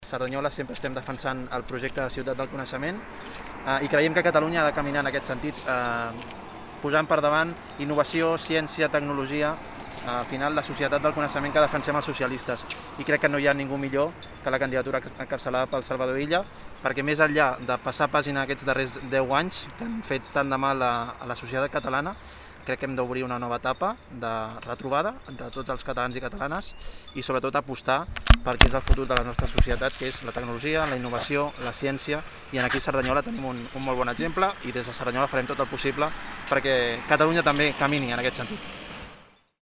Declaracions de Carlos Cordón: